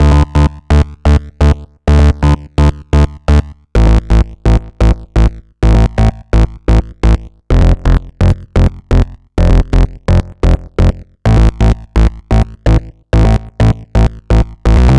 Session 11 - Bass 03.wav